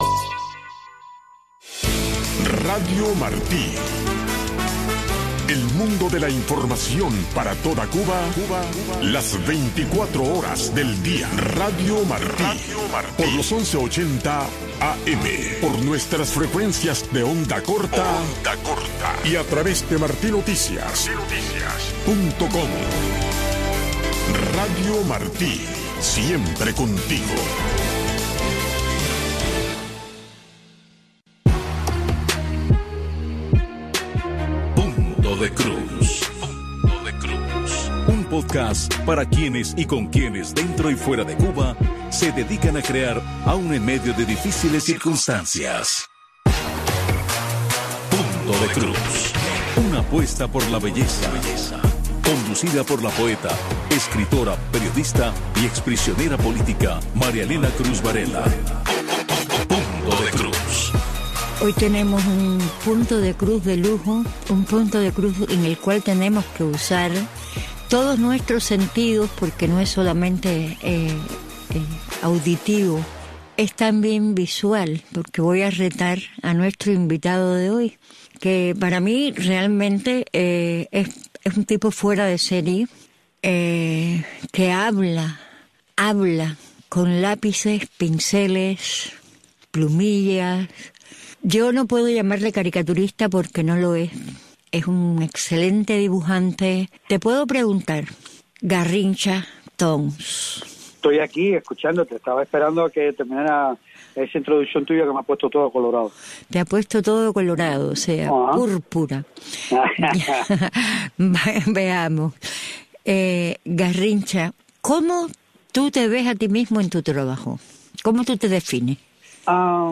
En este espacio informativo de Radio Martí escuche de primera mano los temas que impactan el día a día de los cubanos dentro de la Isla. Voces del pueblo y reportes especiales convergen para ofrecerle una mirada clara, directa y actual sobre la realidad cubana.